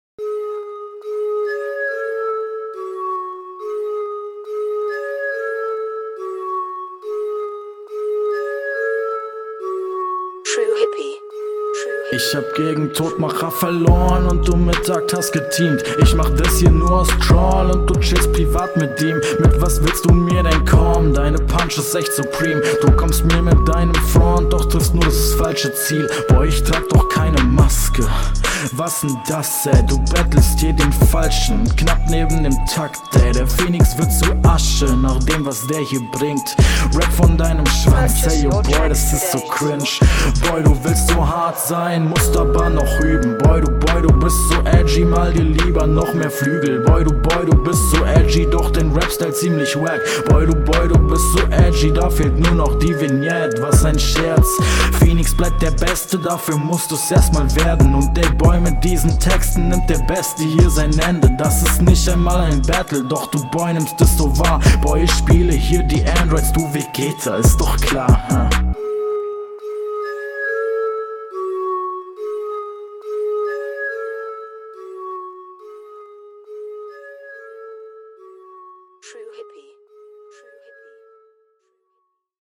Soundbild stabil.
Flow: Der Flow klingt hier routinierter und sicherer, auch der Stimmeinsatz kommt sehr dope.